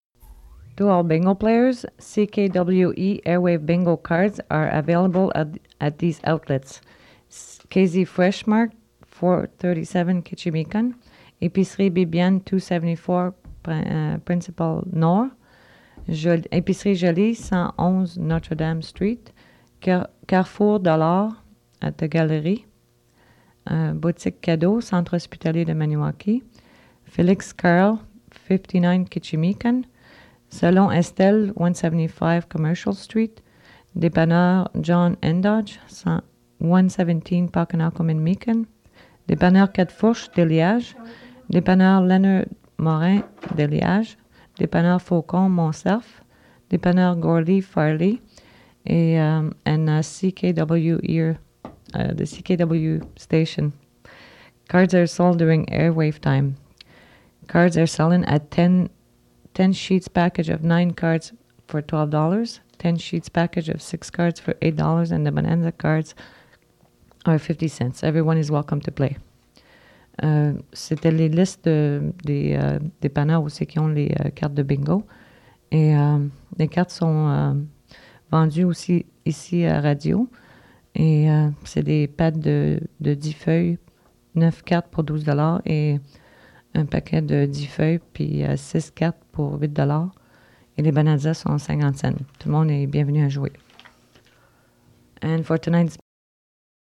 Fait partie de Bingo announcement